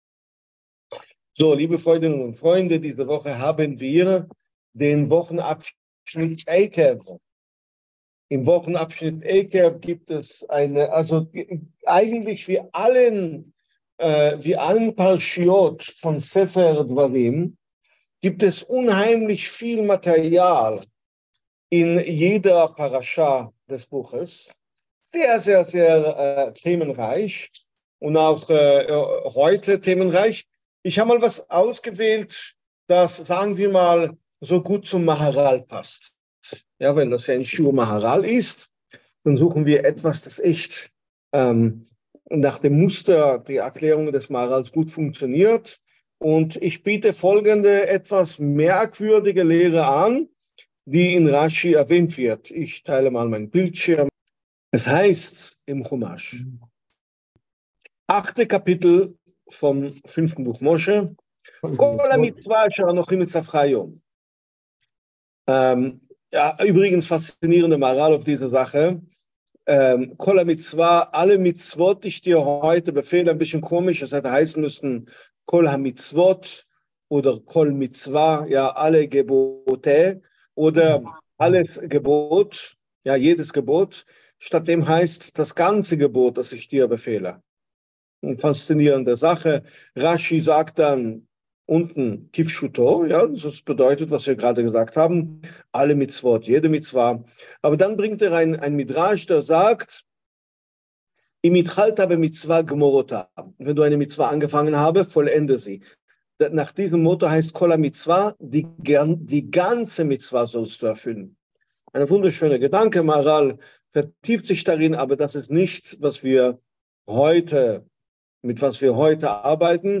(Audio-Aufnahmen der Zoom-Schiurim, noch nicht verlinkte Aufnahmen werden mGH bald hochgeladen)